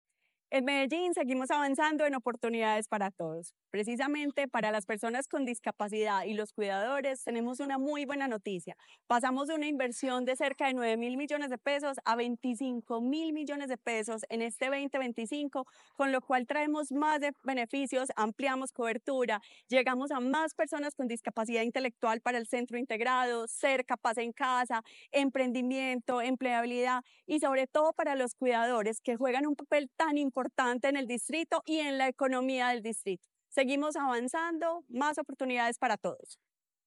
Declaraciones secretaria de Inclusión Social y Familia, Sandra Sánchez.
Declaraciones-secretaria-de-Inclusion-Social-y-Familia-Sandra-Sanchez.-1.mp3